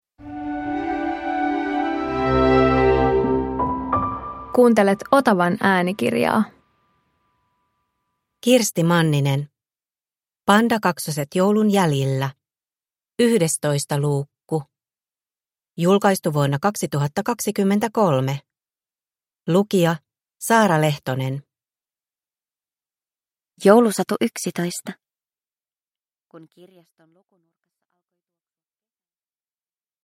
Pandakaksoset joulun jäljillä 11 – Ljudbok